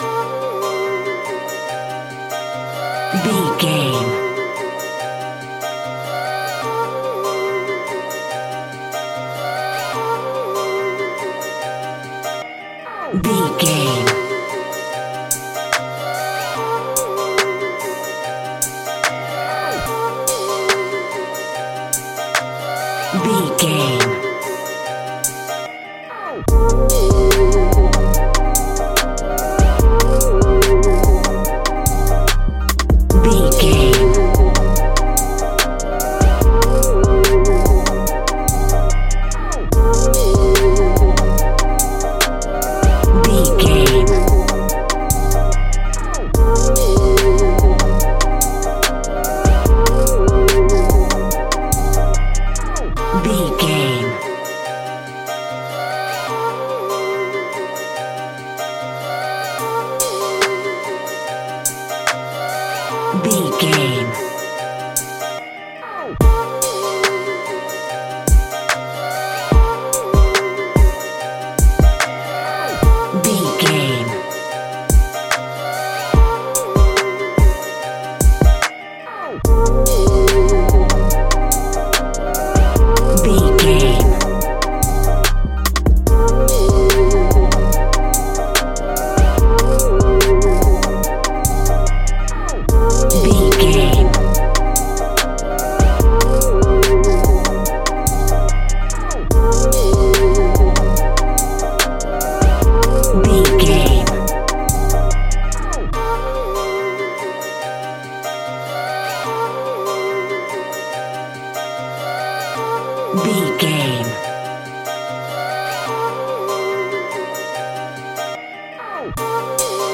Aeolian/Minor
WHAT’S THE TEMPO OF THE CLIP?
aggressive
intense
bouncy
dark
synthesiser
drum machine